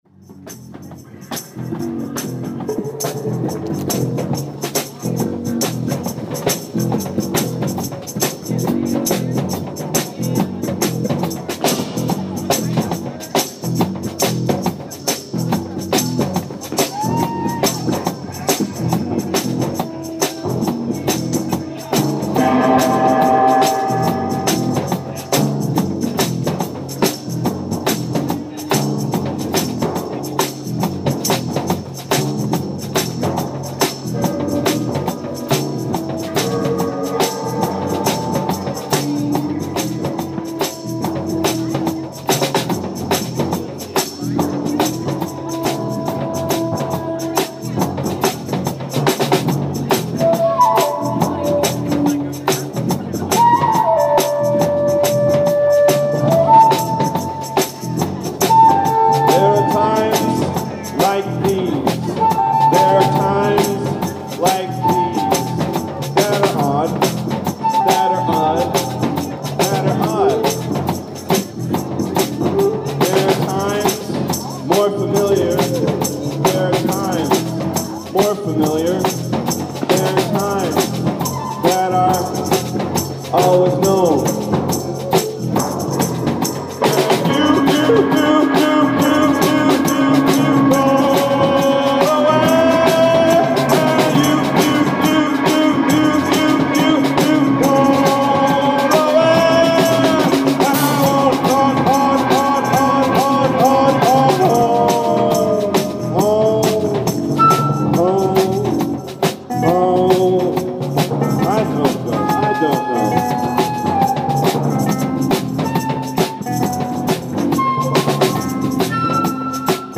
Times More Familiar tympani
bass/bgvoca/electpiano
flute
ALL MUSIC IS IMPROVISED ON SITE